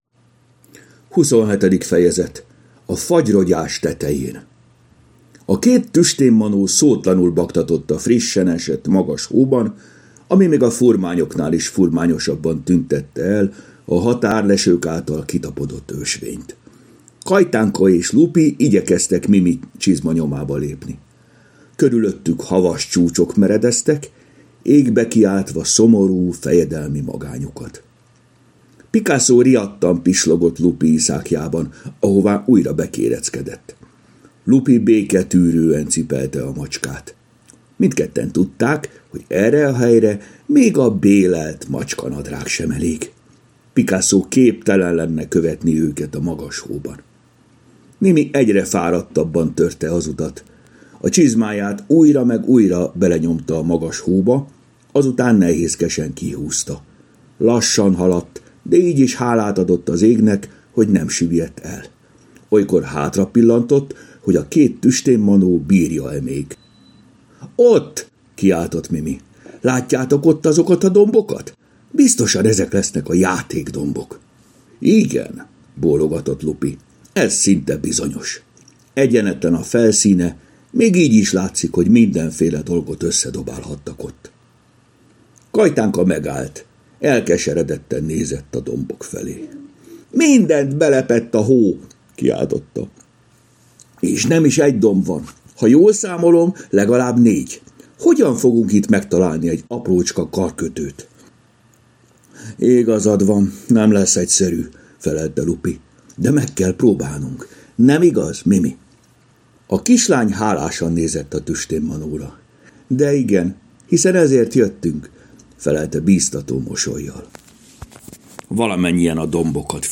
Hangos mese: A Fagyrogyás tetején Mindet meghallgatom ebből a folyamból!